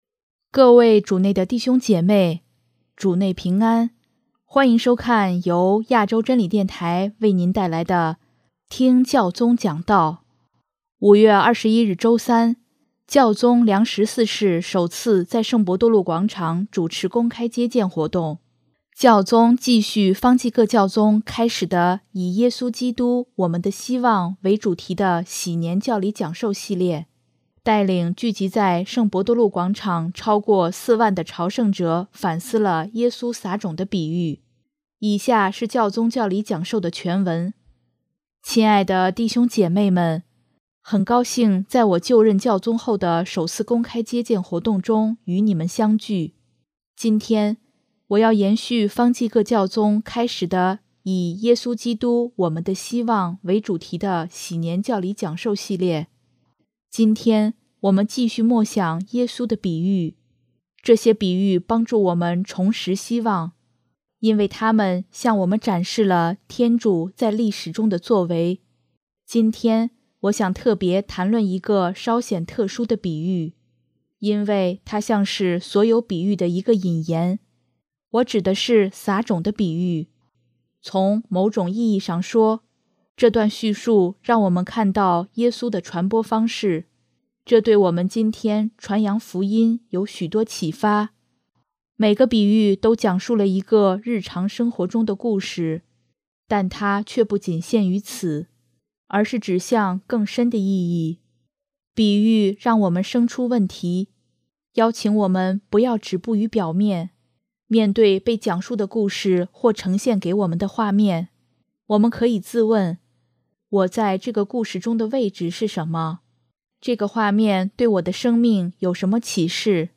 5月21日星期三，教宗良十四世首次在圣伯多禄广场主持公开接见活动。教宗继续方济各教宗开始的以“耶稣基督——我们的希望”为主题的禧年教理讲授系列，带领聚集在圣伯多禄广场超过4万的朝圣者反思了耶稣撒种的比喻。